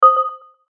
ボタン・システム （87件）
決定4.mp3